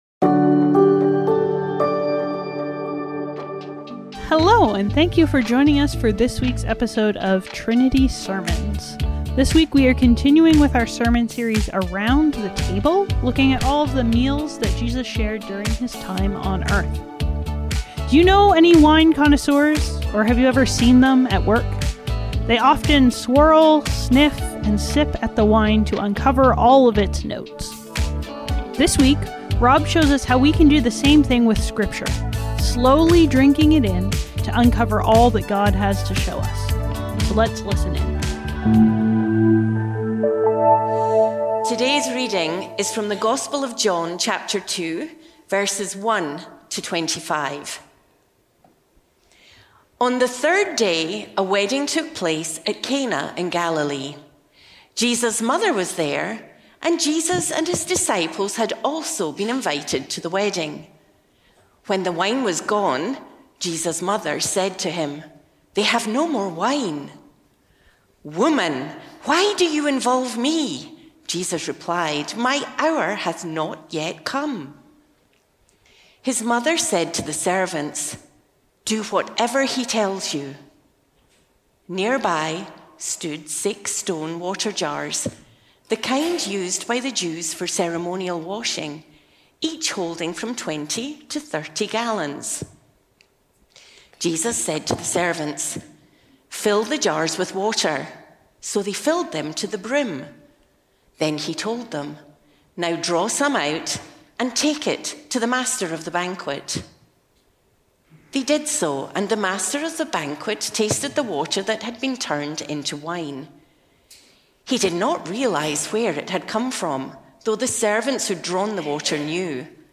Trinity Streetsville - Bottoms Up | Around The Table | Trinity Sermons